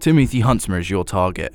Update Voice Overs for Amplification & Normalisation